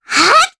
Luna-Vox_Casting3_jp_b.wav